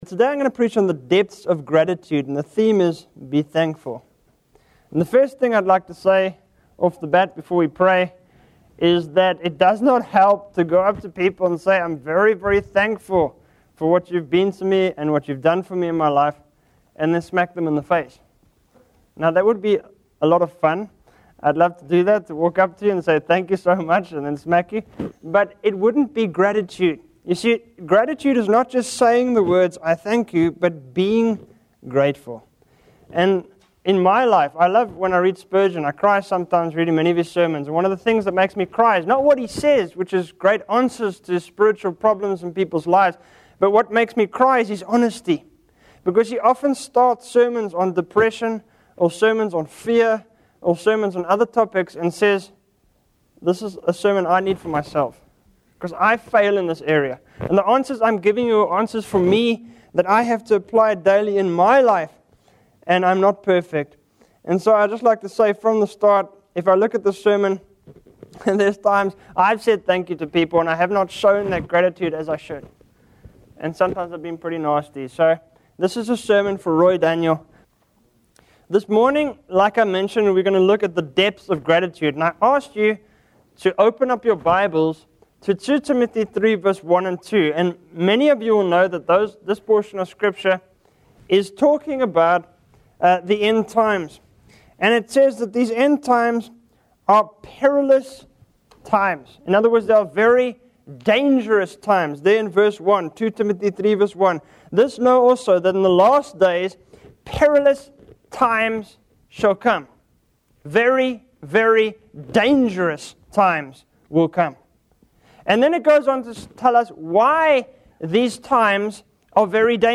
In this sermon, the preacher discusses the dangers of forgetting God and becoming prideful in one's own achievements. He emphasizes the importance of gratitude and acknowledges his own shortcomings in showing gratitude. The preacher references 2 Timothy 3:1-2, which talks about the perilous times of the end.